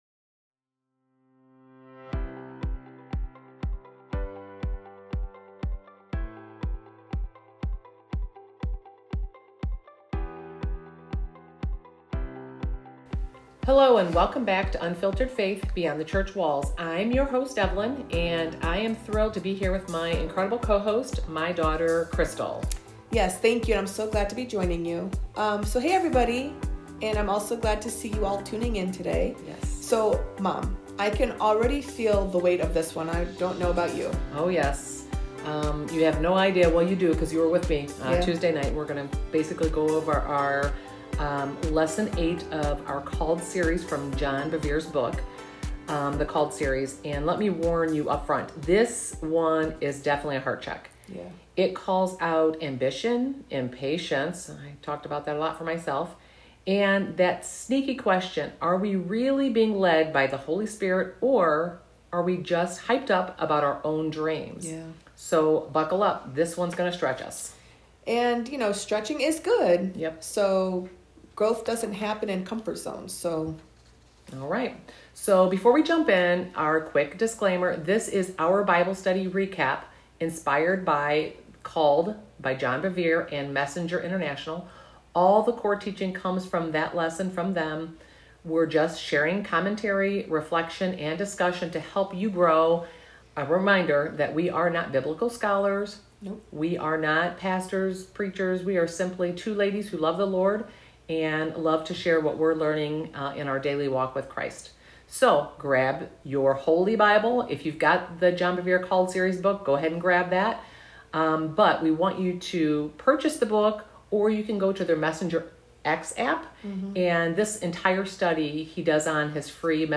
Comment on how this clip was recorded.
This is our personal reflection and group discussion from our Tuesday night Bible study at Beyond the Church Walls Ministry.